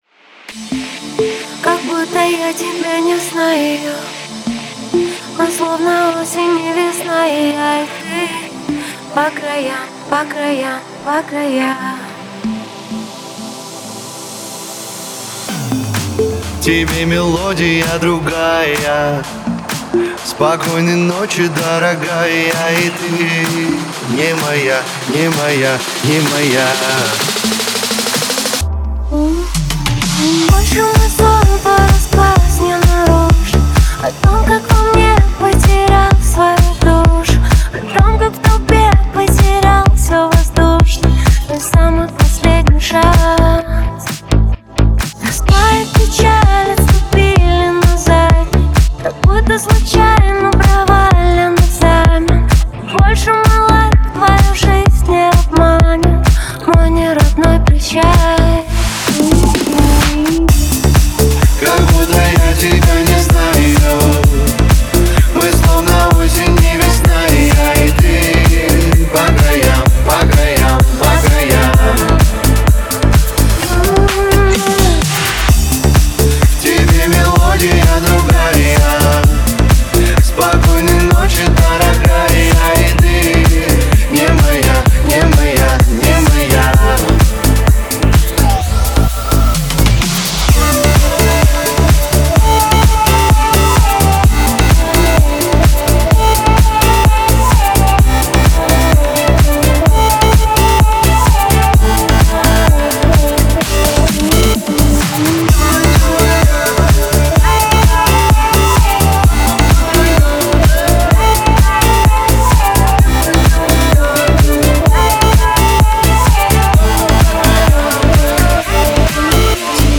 это динамичная поп-электронная композиция